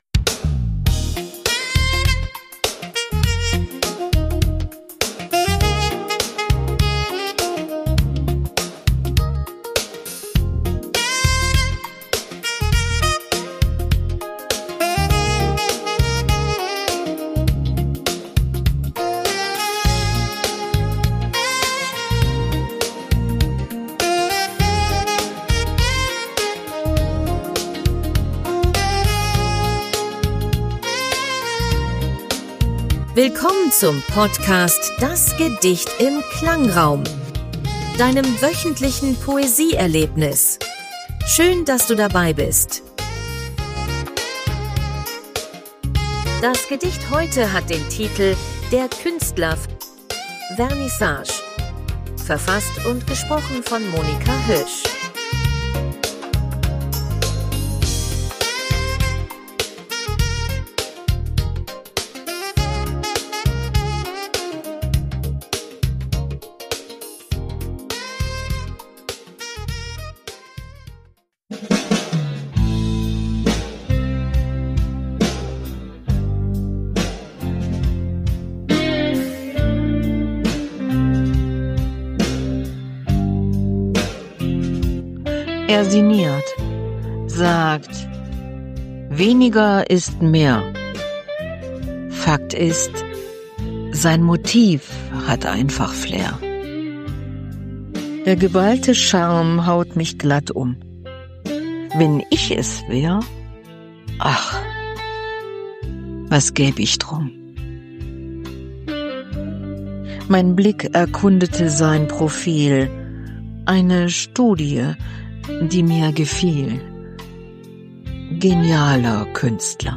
Die Hintergrundmusik wurde mit
KI-generiert.